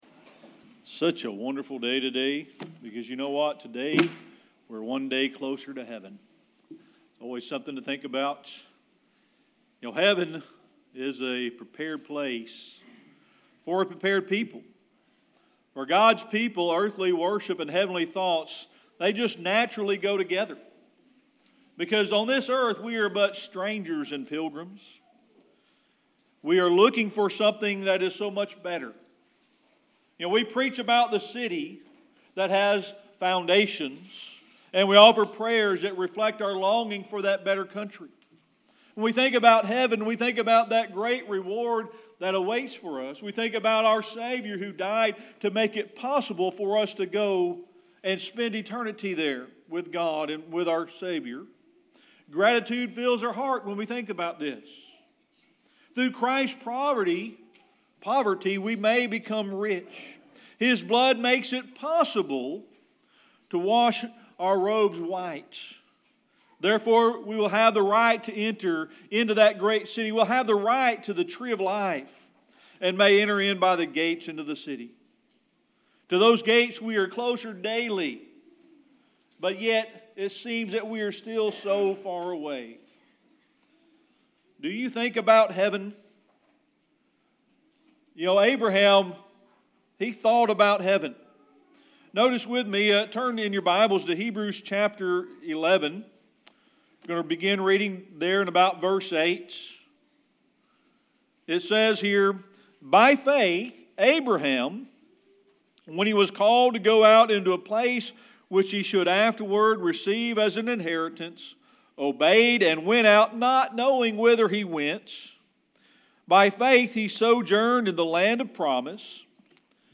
This powerful sermon explores the concept of Heaven as a place of rest, peace, and happiness for the soul.
Revelation 22 Service Type: Sunday Morning Worship It is such a wonderful day today because you know what?